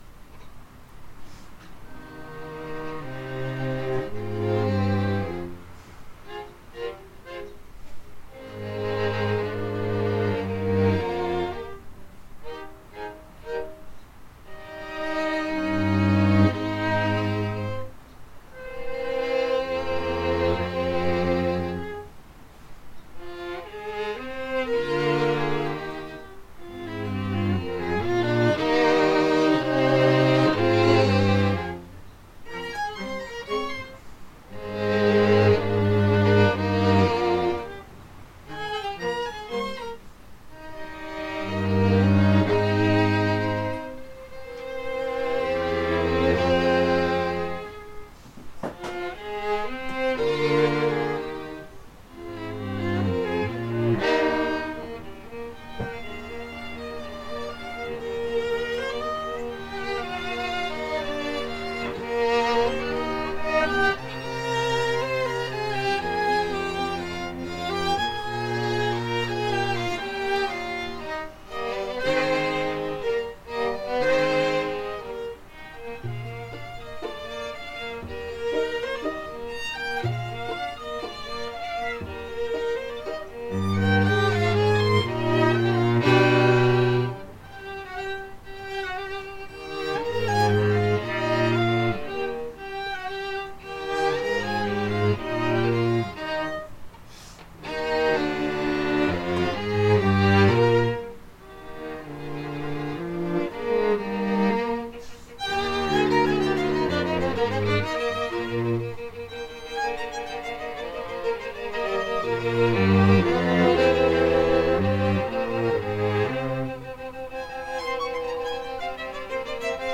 the students
Chamber Groups